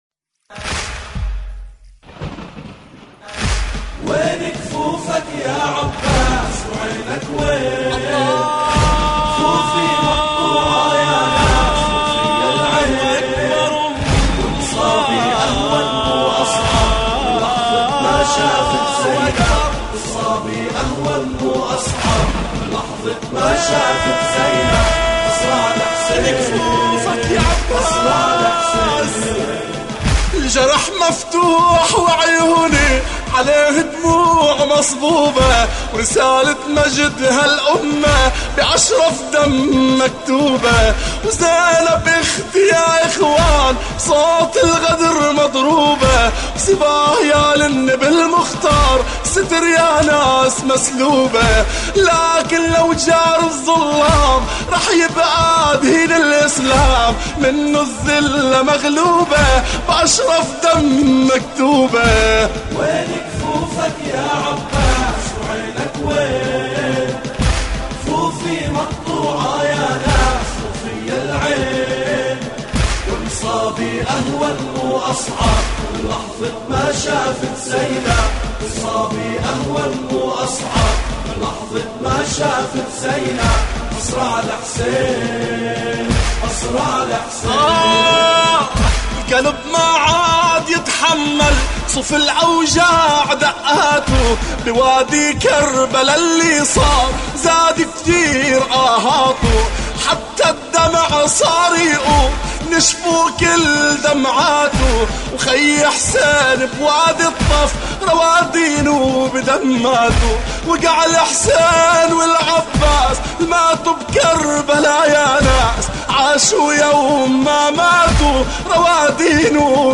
مراثي أبو الفضل العباس (ع)